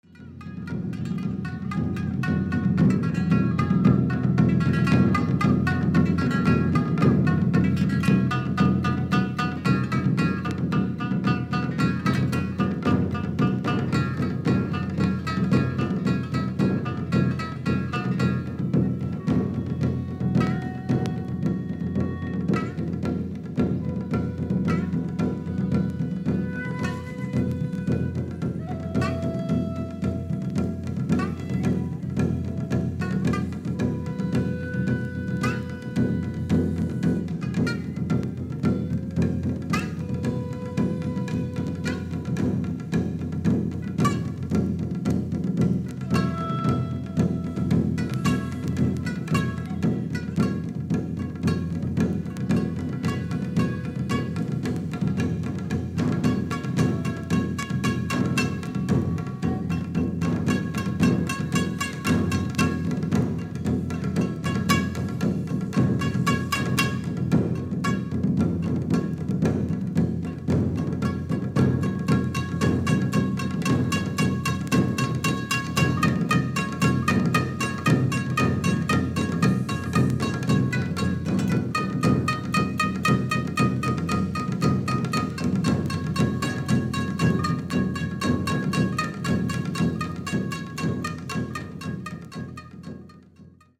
Japanese Traditional